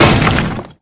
pickaxe.mp3